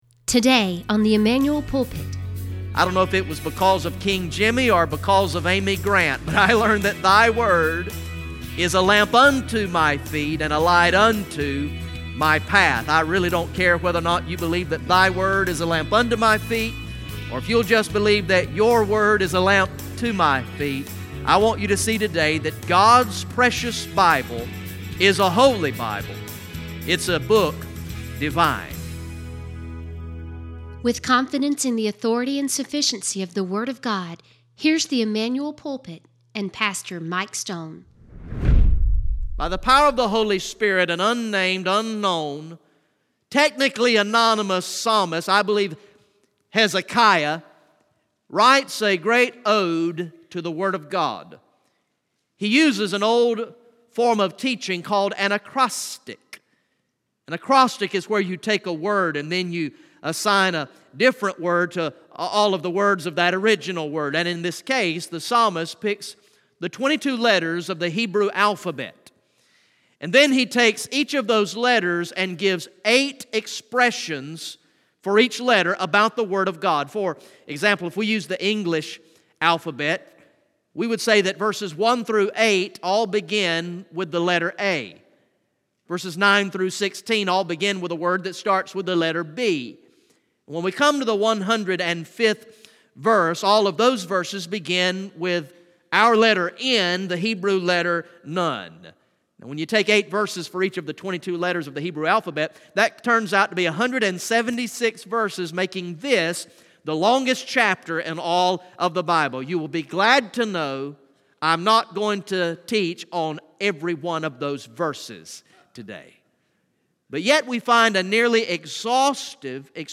From the morning worship service on Sunday, February 4, 2018